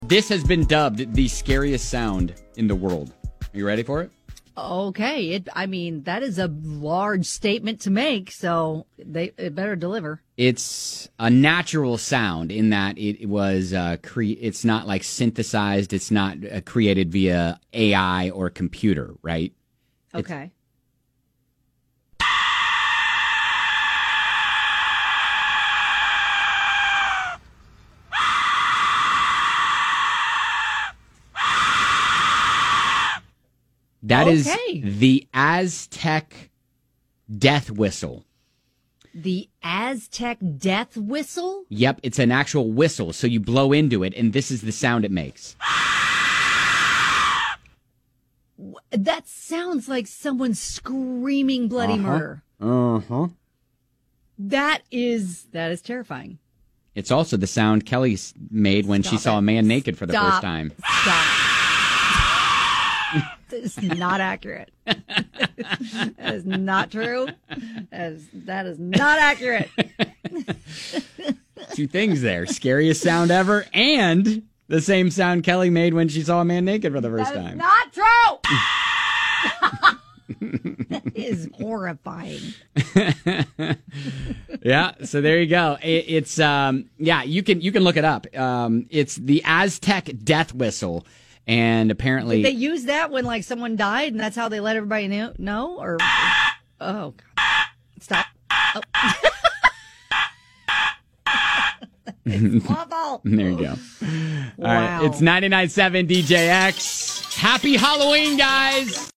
The Aztec Death Whistle has been dubbed the "scariest" sound in the world!